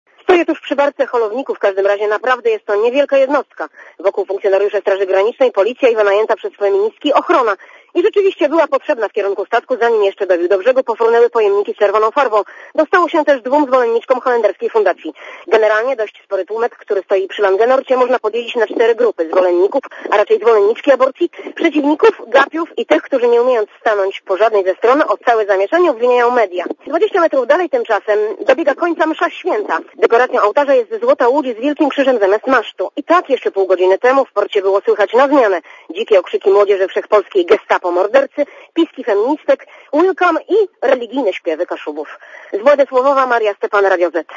Na miejscu była reporterka Radia Zet (176Kb)